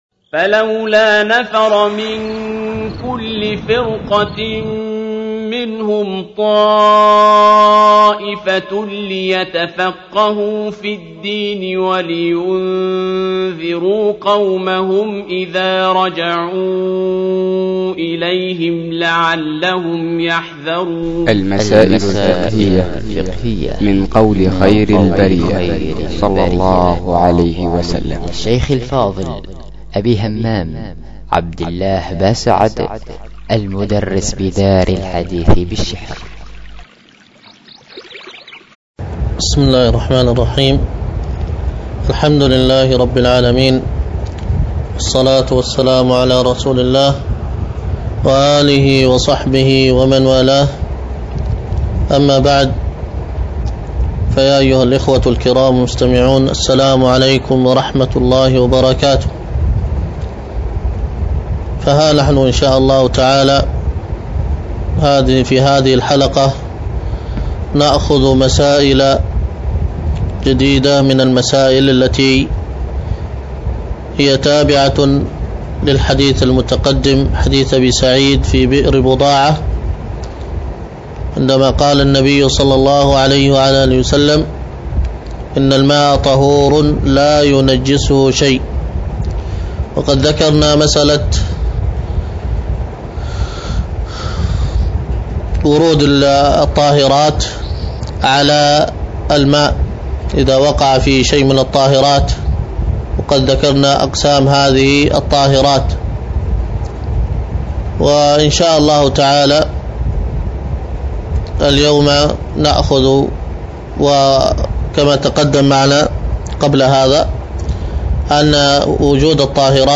المسائل الفقهية من قول خير البرية 20 | المسائل الفقهية من قول خير البرية - برنامج إذاعي ( مكتمل )